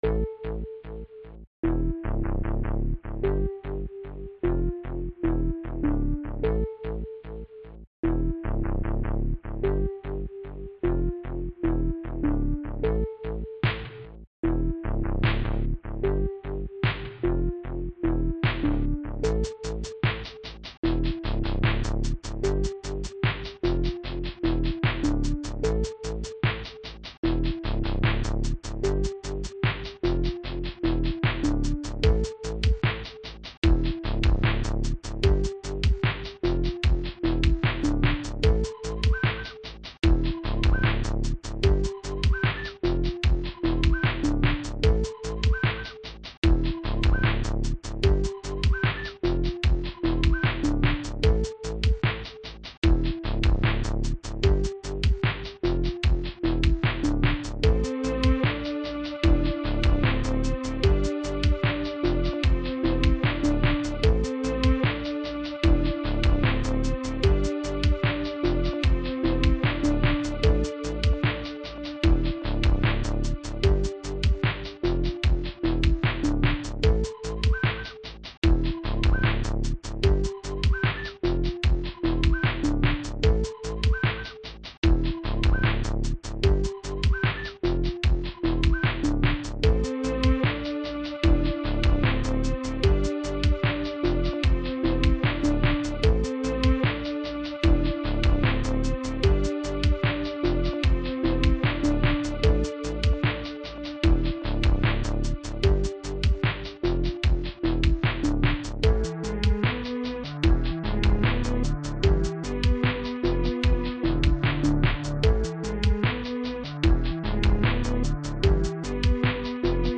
It was written for much slower processor speeds (have you ever heard of a 386?) so I've remastered it a bit to compensate. Sounds decent.